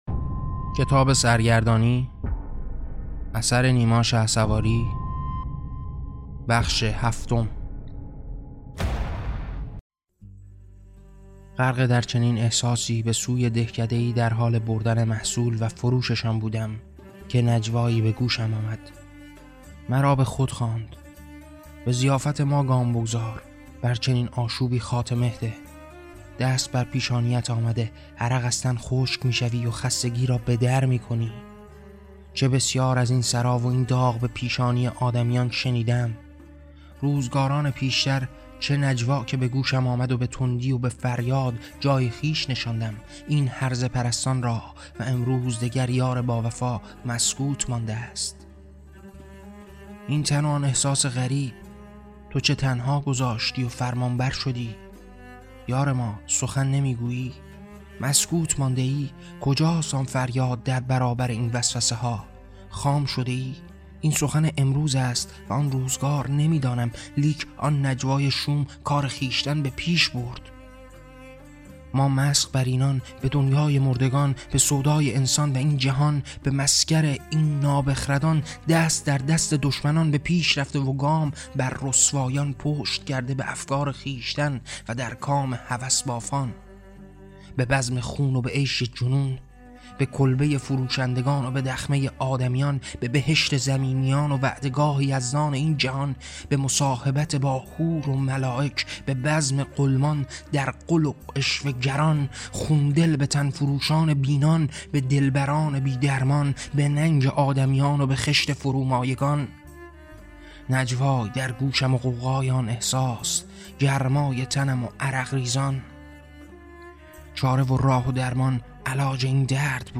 کتاب صوتی سرگردانی - بخش هفتم